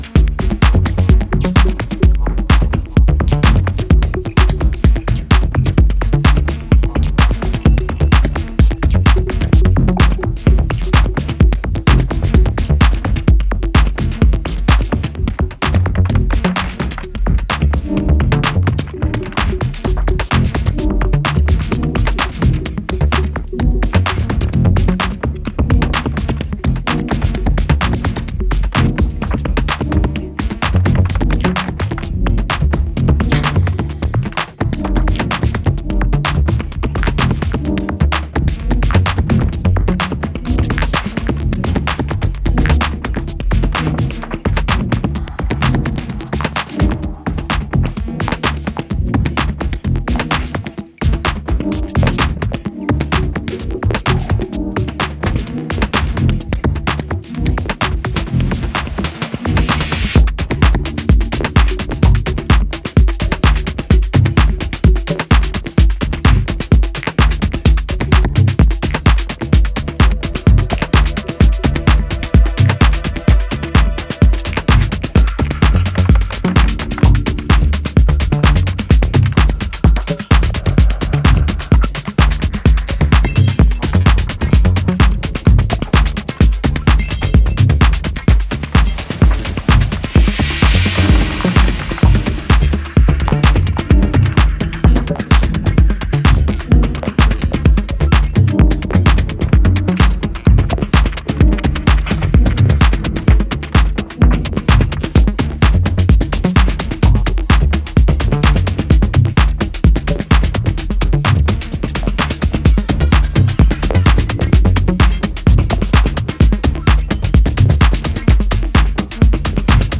ダビーなボトムの奥からトリッピーな音色が迫る瞬間に身震いするB面は、緊迫感溢れるアブストラクト・ミニマル。